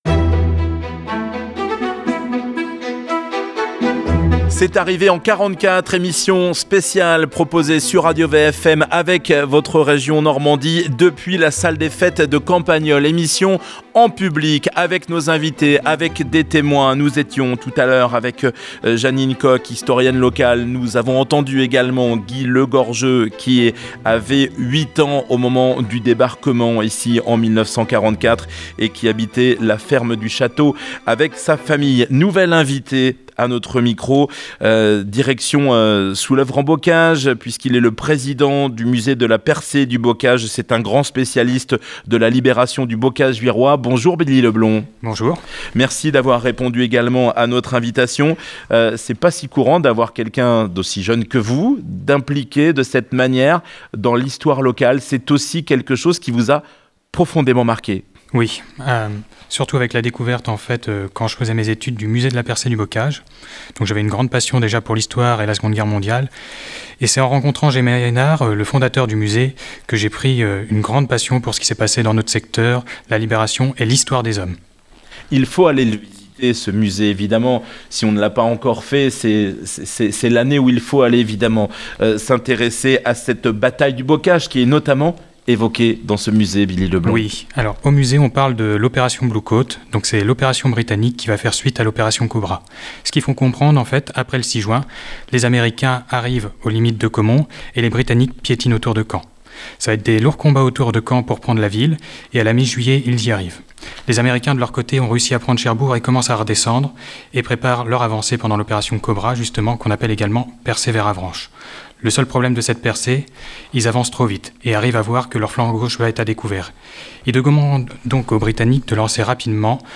Émission exceptionnelle diffusée Samedi 15 Juin à Campagnolles à 10 h 15. Témoignages, récits de la libération du Bocage.